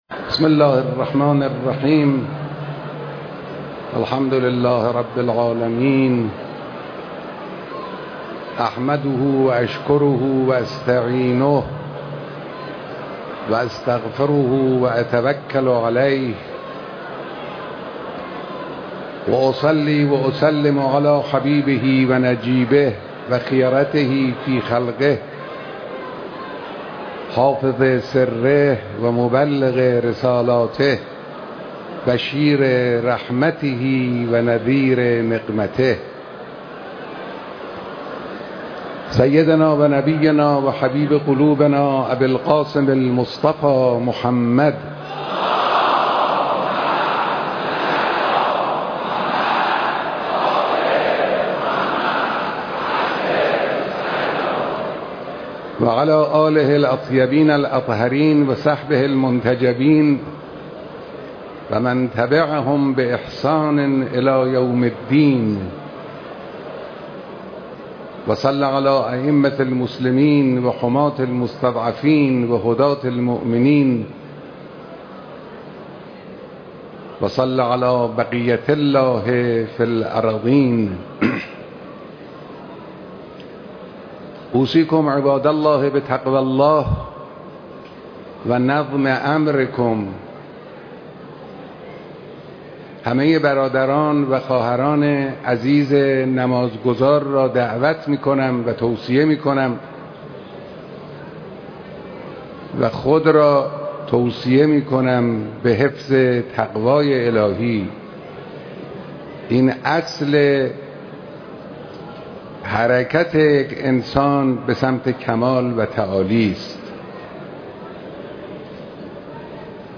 اقامه نمازجمعه تهران به امامت حضرت آیت الله خامنه ای
خطبه اول نماز جمعه